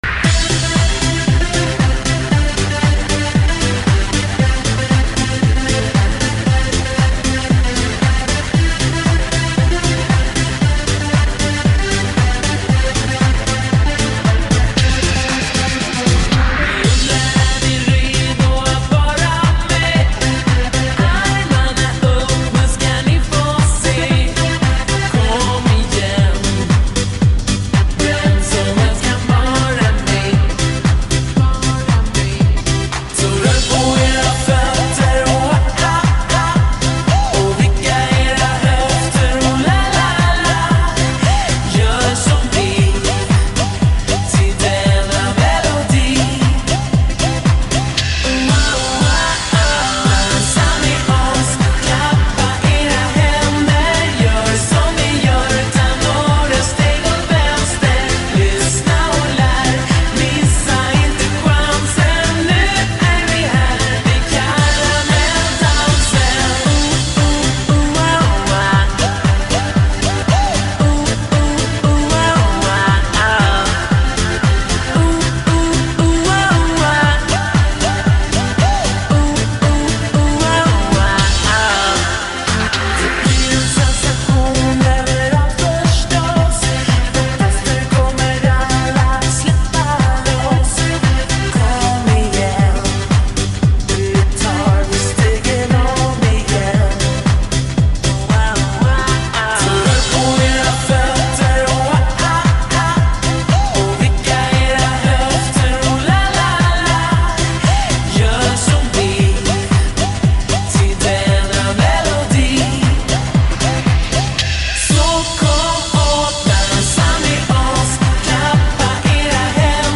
دانلود نسخه Slowed و کند شده آهنگ
شاد